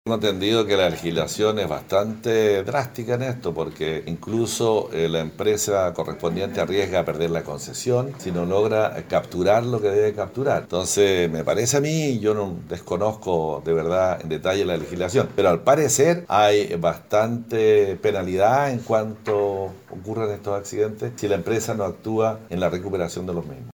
Intendente se refiere a fuga de salmones
10-INTENDENTE-SALMONES-2.mp3